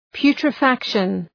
Προφορά
{,pju:trə’fækʃən}